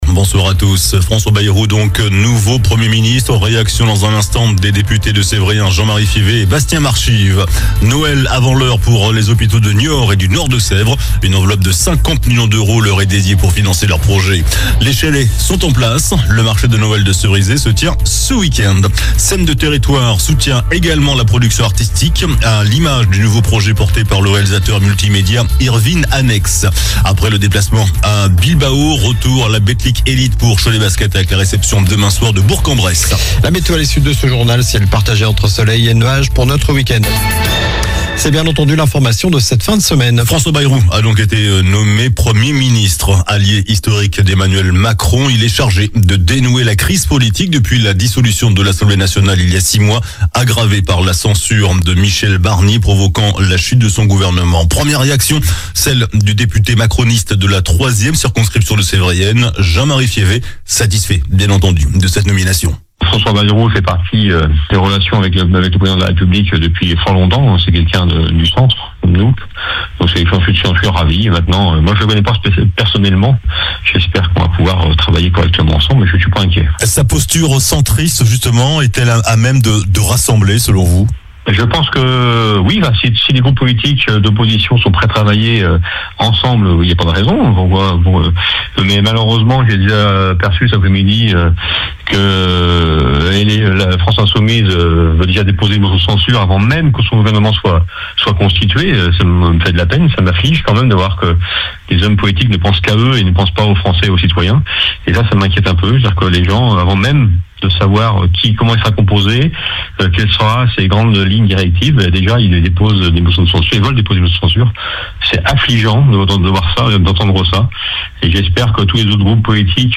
JOURNAL DU VENDREDI 13 DECEMBRE ( SOIR )